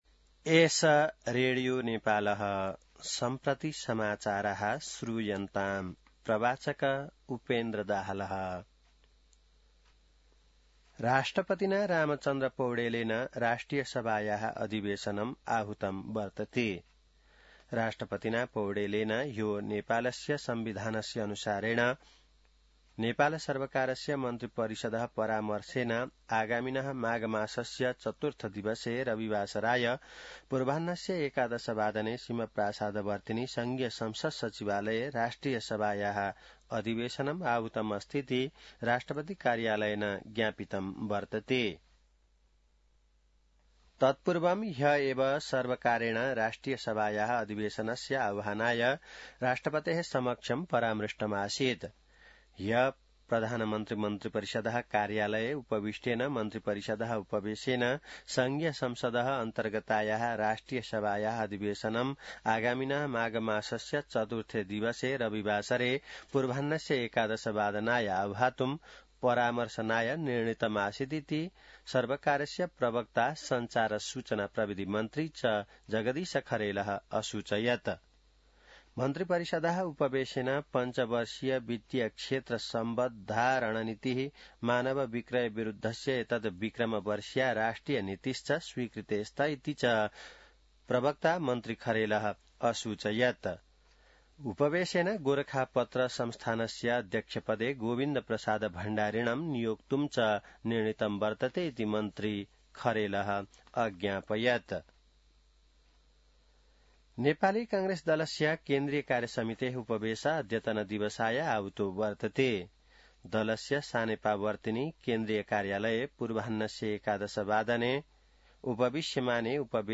संस्कृत समाचार : २९ पुष , २०८२